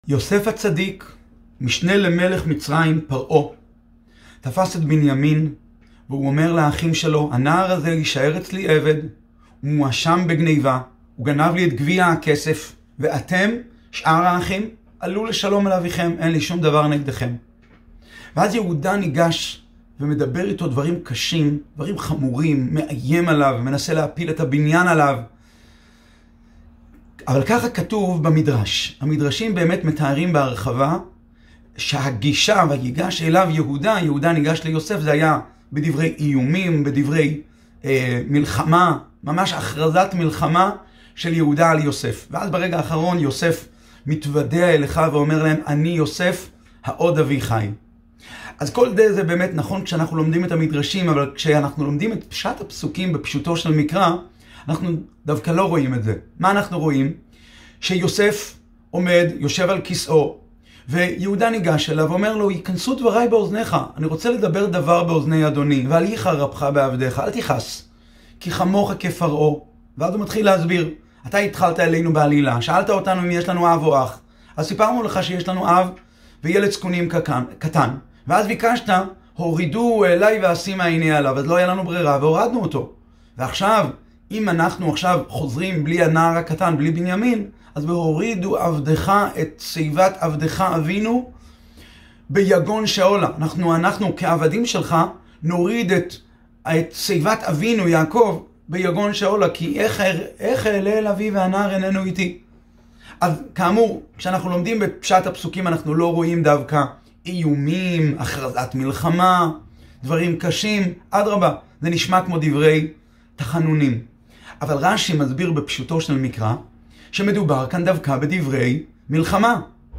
שיעור בעיון עברית ויגש אליו יהודה.